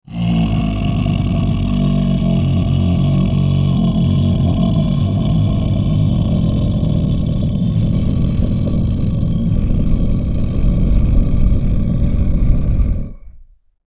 Scary Monster Growl Roar 12 Sound Button - Free Download & Play